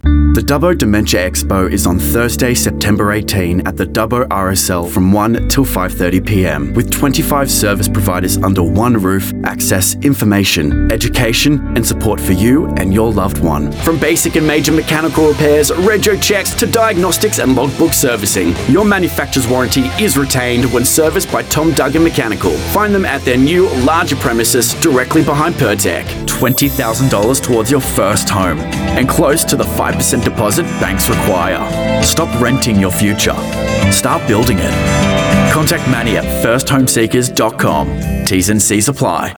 British Radio & TV Commercial Voice Overs Artists
Yng Adult (18-29)